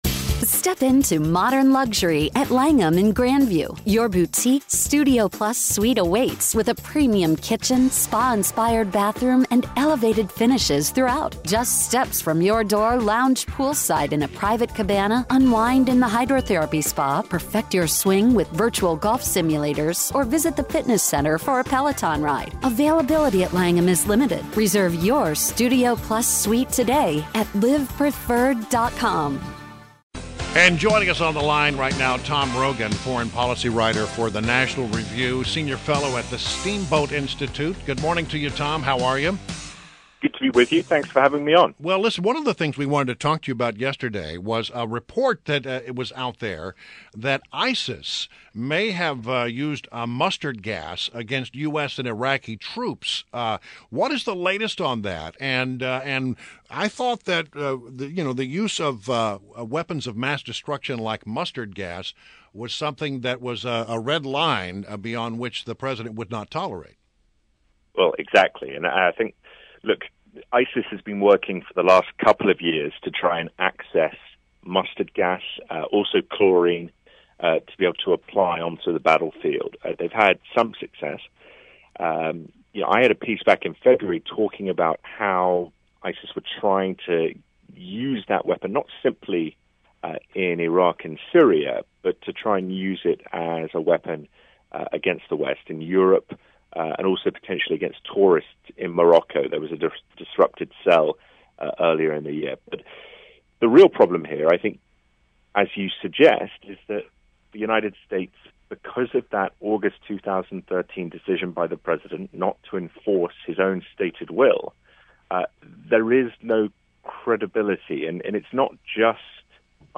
Mornings on the Mall / WMAL Interview